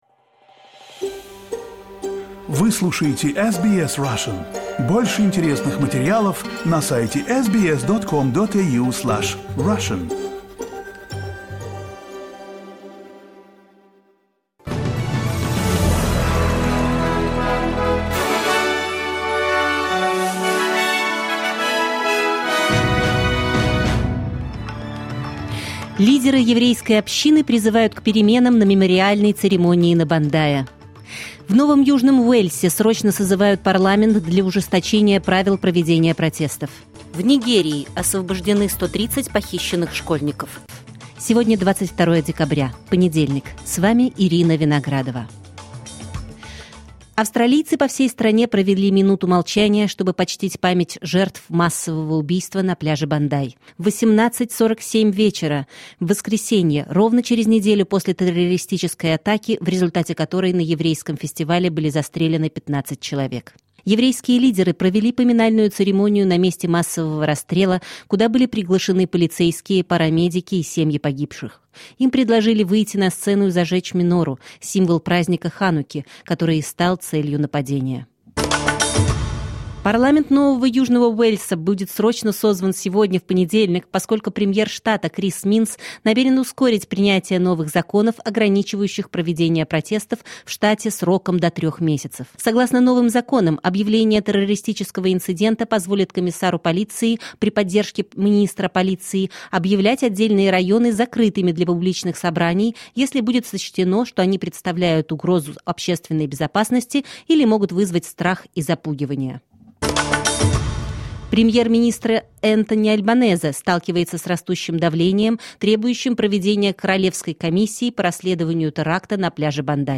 Новости SBS на русском языке — 22.12.2025